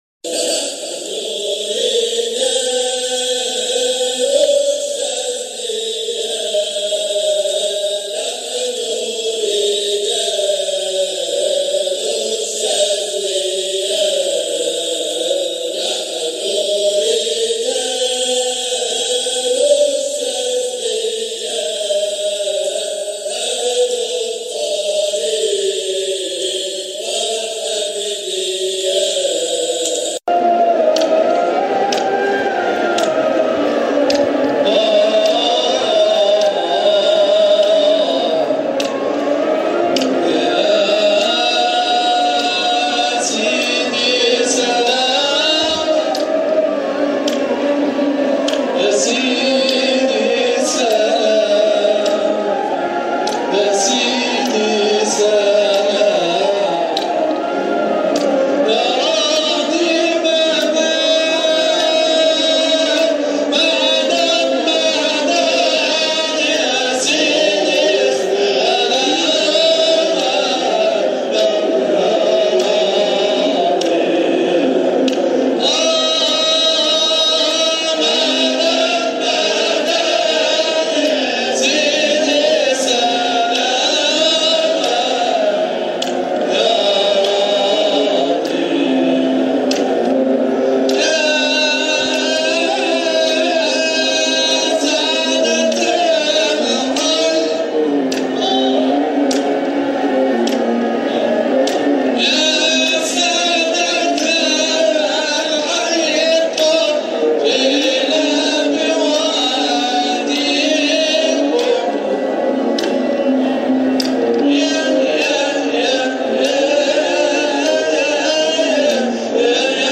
جزء من حلقة ذكر بمولد سيدنا احمد البدوى قدس سره 2018